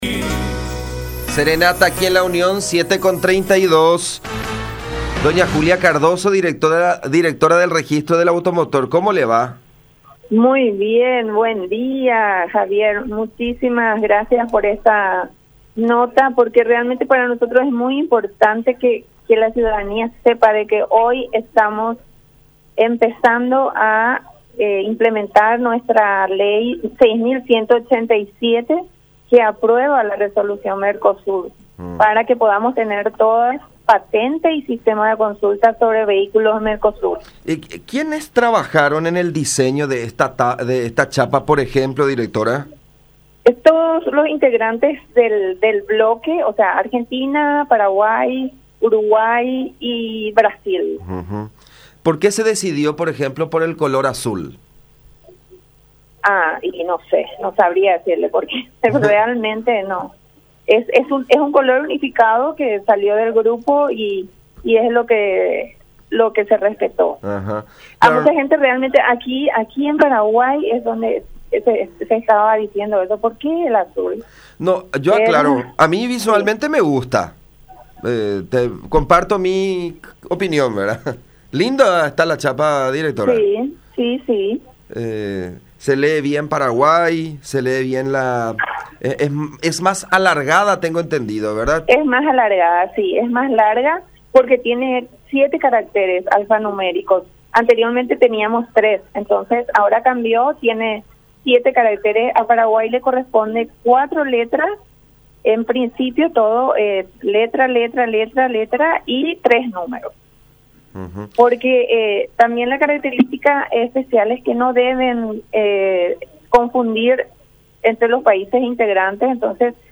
09-Julia-Cardozo-Directora-del-Registro-del-Automotor.mp3